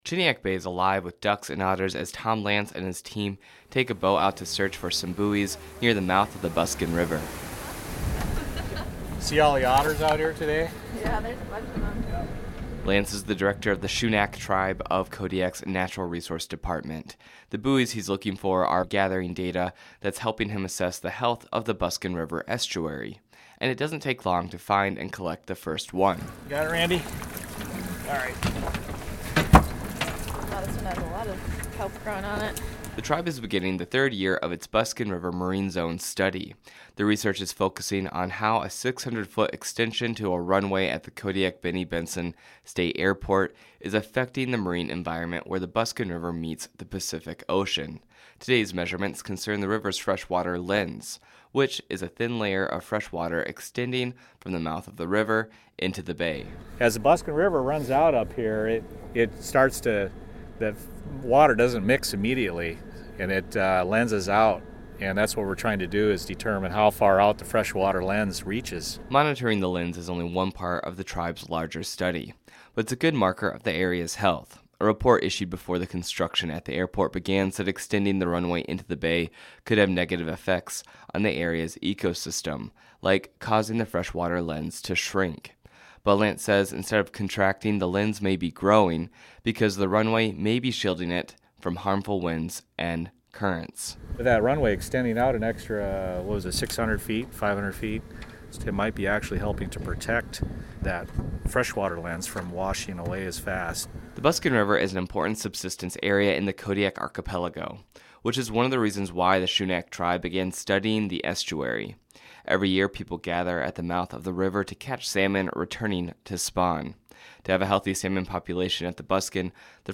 2018 News